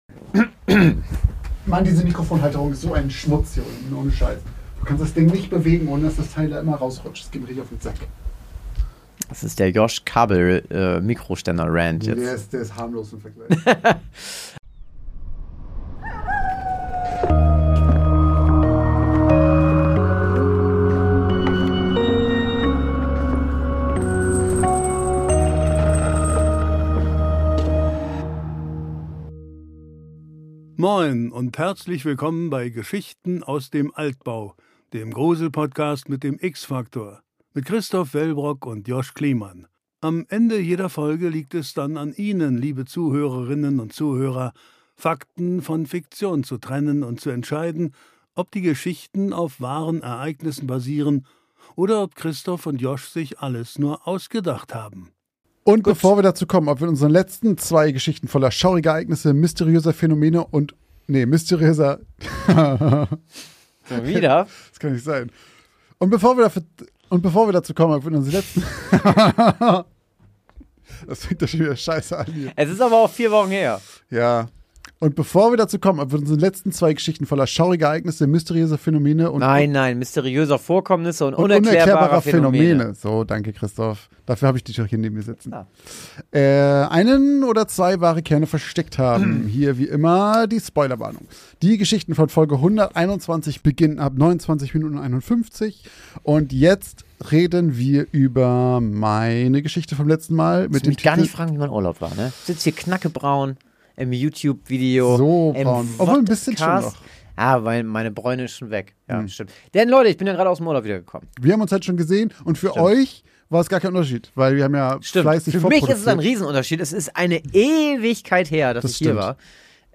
Beide hören zum ersten Mal gemeinsam mit ihren Zuhörenden die Geschichte des jeweils anderen.